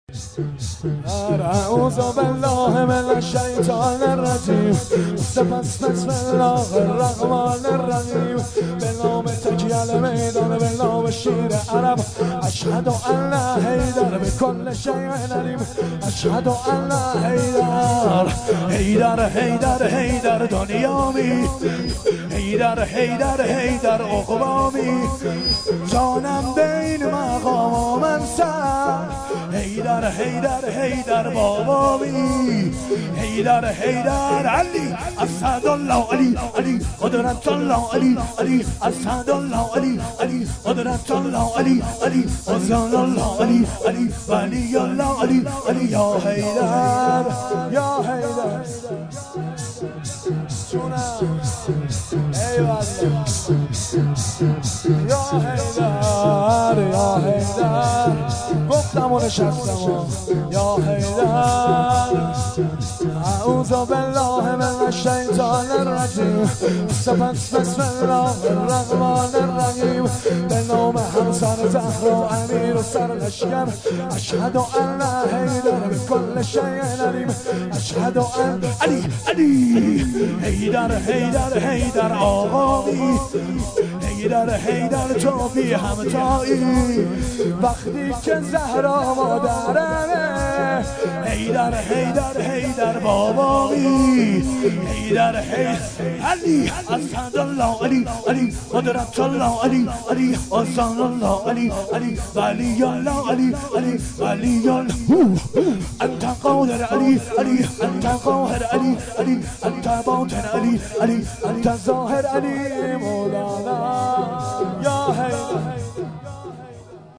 شور
شب هفتم محرم ۱۴۴۱